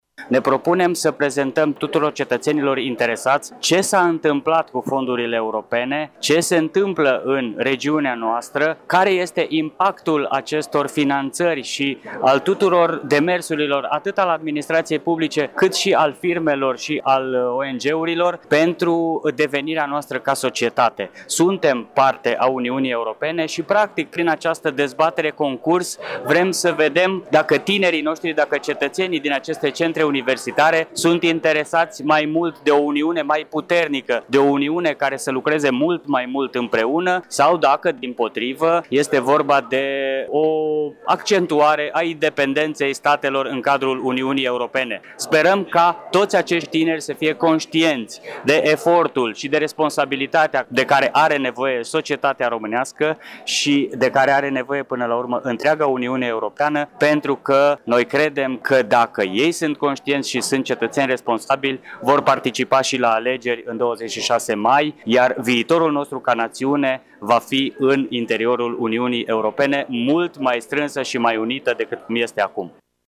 Aula Magna a Universității de Medicină, Farmacie, Științe și Tehnologie (UMFST) din Tg.Mureș a găzduit, azi, cea de-a treia dezbatere despre Viitorul Europei organizată de Centrul de Informare Europe Direct- Regiunea Centru.